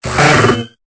Cri de Lanturn dans Pokémon Épée et Bouclier.